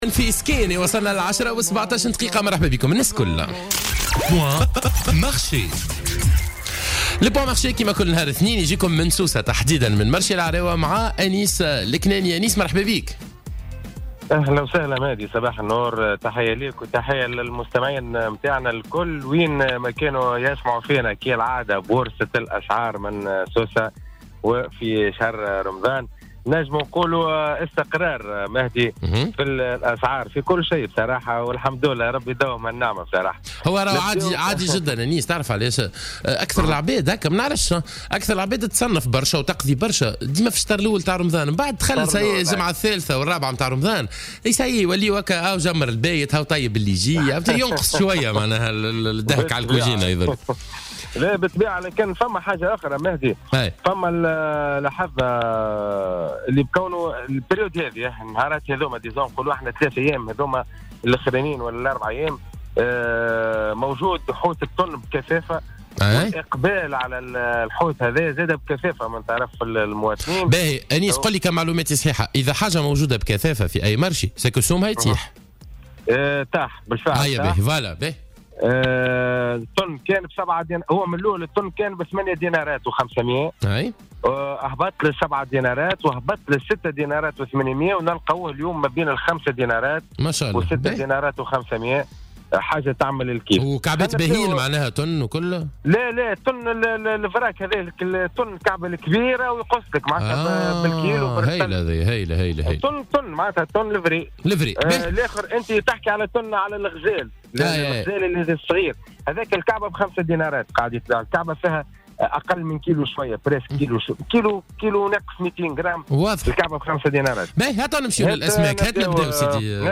وأكد موفدنا الذي تحوّل إلى سوق "العراوة" في سوسة المدينة لرصد الأسعار، أكد لبرنامج "رمضان المدينة"، تراجع اسعار الأسماك بشكل ملحوظ خاصة صنف التن الذي تراجع من 8.5 دينار إلى 5 دينارات بالاضافة إلى انخفاض كبير في سعر الدقلة الذي تراجع إلى 8.5 د الكيلوغرام بعد تجاوزه الـ10 د. تعرّف على باقي الأسعار في التسجيل الصوتي المرفق: